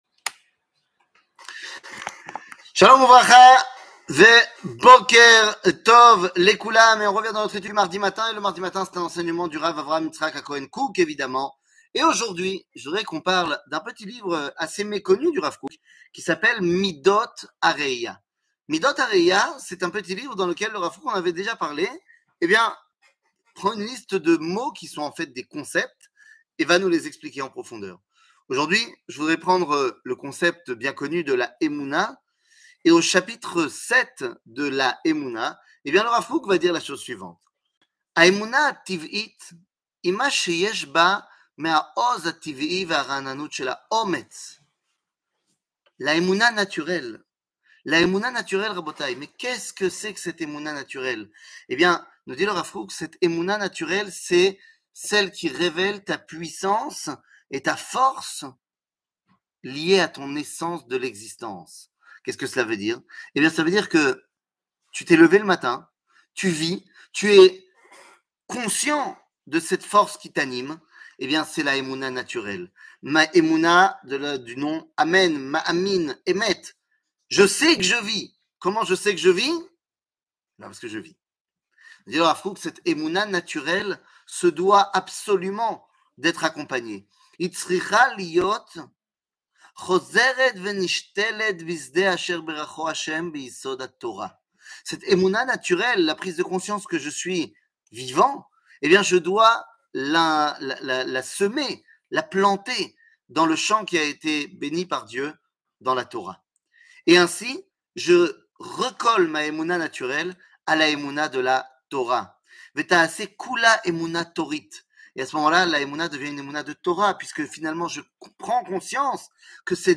שיעור מ 28 יוני 2022